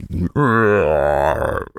hippo_groan_01.wav